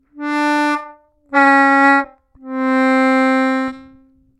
-1 -1’+1  отрывисто